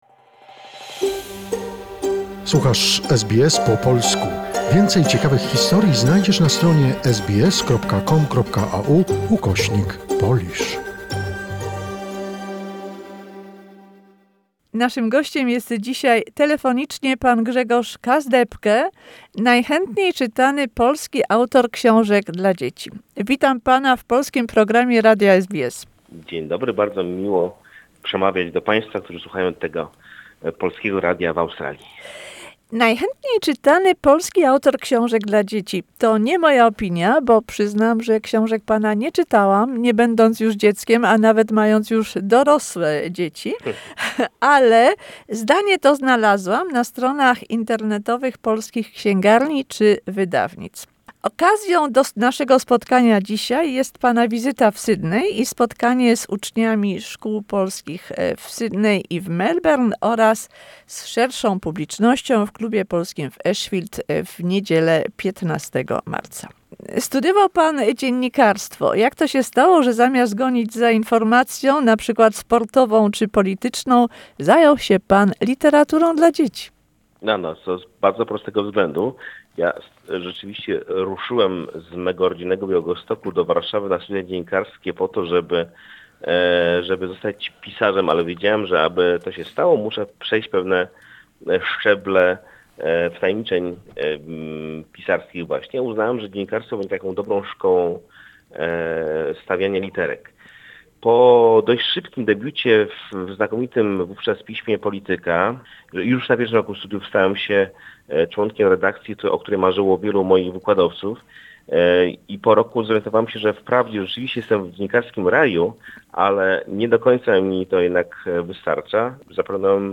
Interview with the most popular Polish writer for children, Knight of the Order of the Smile, Grzegorz Kasdepke, who will visit Australia next week and meet with his readers.